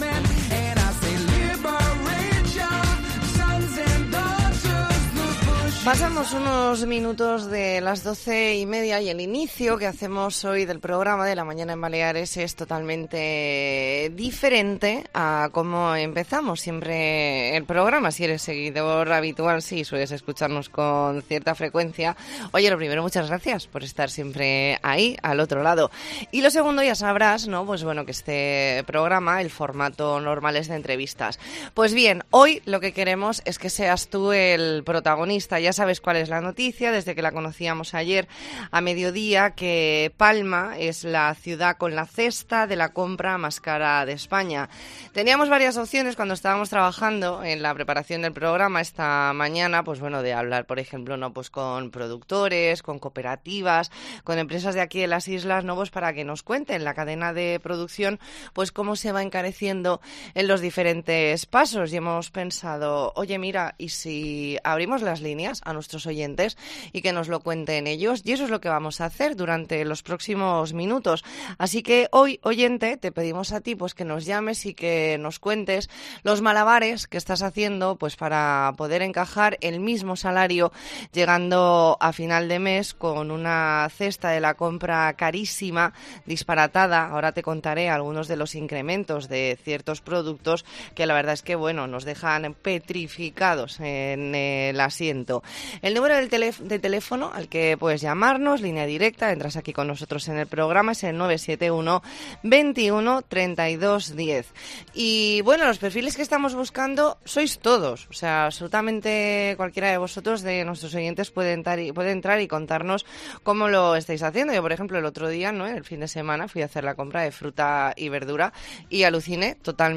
E ntrevista en La Mañana en COPE Más Mallorca, miércoles 28 de septiembre de 2022.